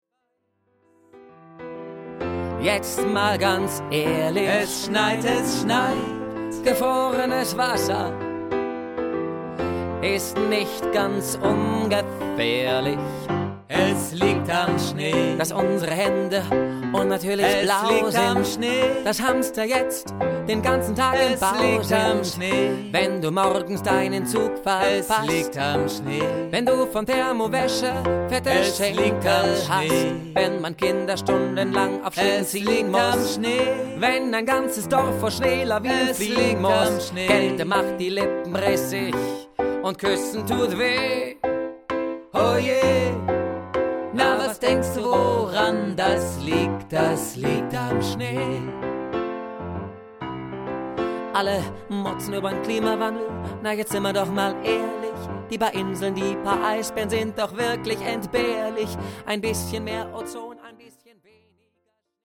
In ihrer heiteren Anti-Weihnachts-Show zertrümmern Malediva unter dem Gelächter ihres Publikums das Festtagsgeschirr, um es anschließend wieder zu etwas ganz Neuem zusammenzusetzen (Unser Baum ist hässlich? Sagen wir lieber, er ist günstig!).
Und mit ihren wunderschönen Liedern und ihrem warmherzigen Humor berühren sie auch an grimmig kalten Winterabenden so manches Herz und machen in dieser würzigen Mischung das Fest selbst für Weihnachtsmuffel erträglich.
Sie verbinden das gnadenlos perfekte Timing der Stand-Up-Comedy mit swingenden Deutsch-Pop-Perlen und einer mitreißenden Freude an der Improvisation.